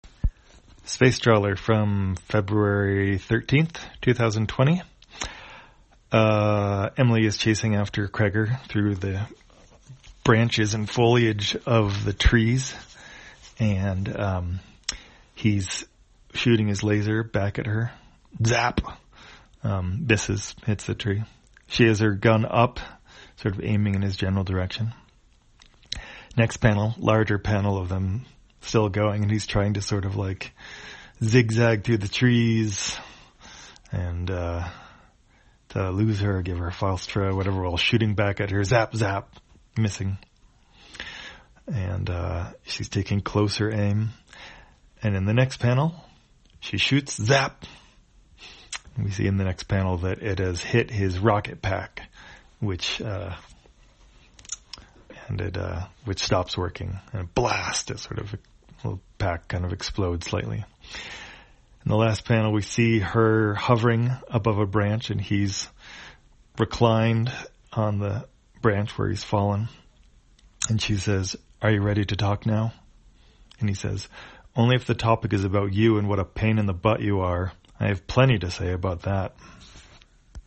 Spacetrawler, audio version For the blind or visually impaired, February 13, 2020.